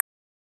Clicks